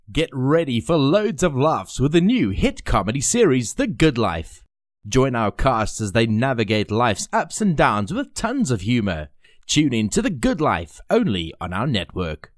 My voice is clear, engaging, versatile, and perfect for:
Fast turnaround. Studio-quality sound. No fuss, just clean, compelling audio tailored to your vision.